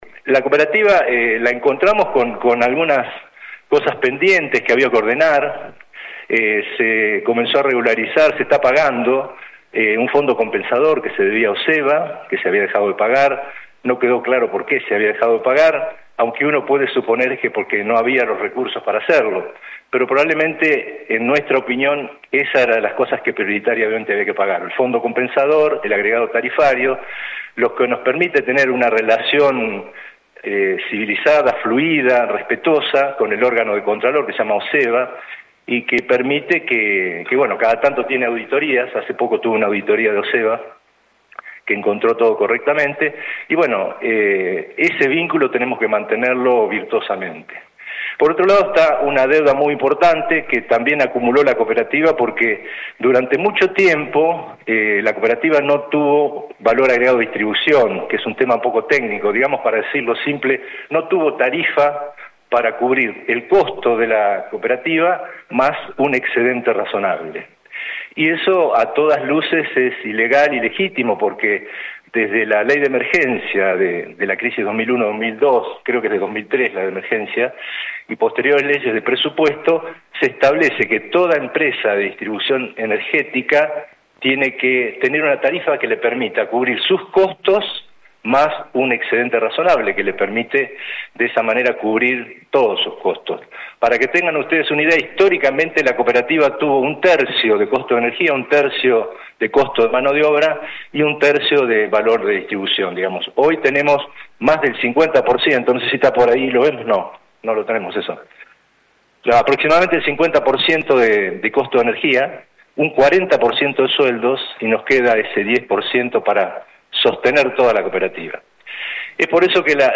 Los importantes datos, se dieron a conocer ayer en la conferencia de prensa luego de una breve reseña histórica de lo que es la Cooperativa y en la forma en que se fundó.